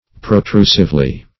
Pro*tru"sive*ly